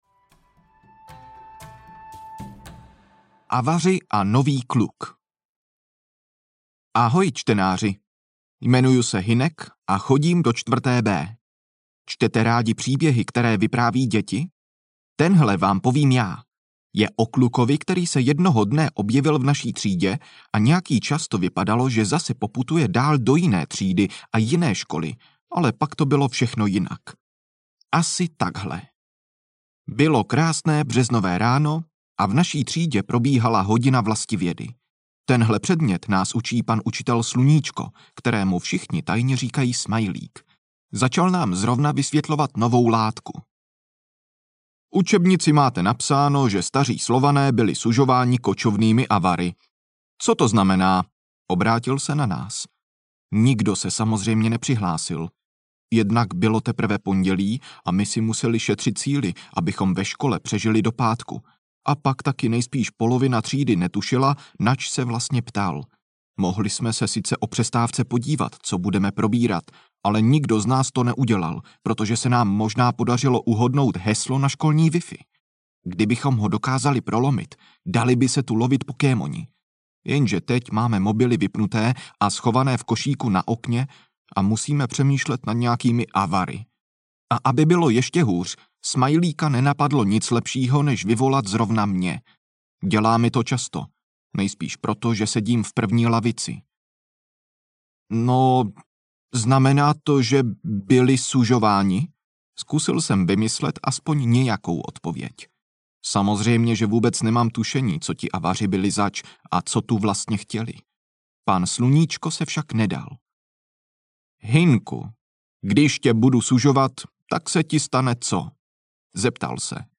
Ukázka z knihy
pravidla-skutecnych-ninju-audiokniha